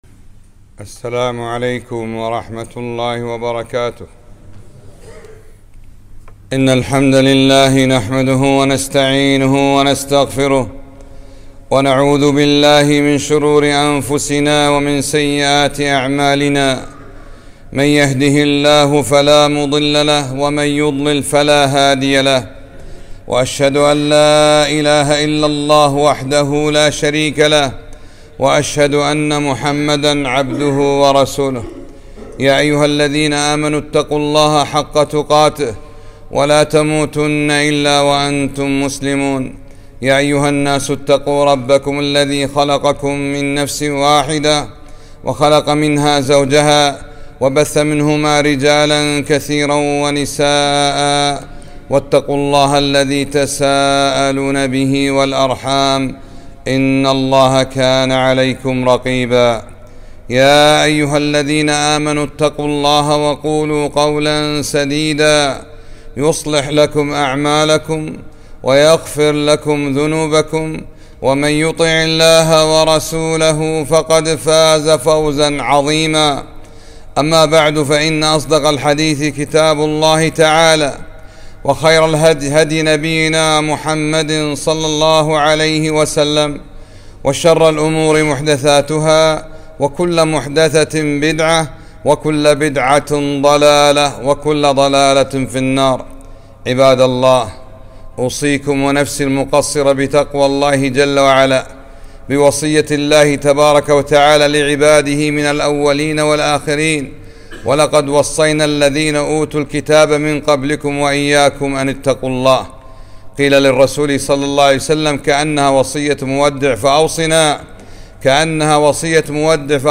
خطبة - اليس لك عند الله حاجة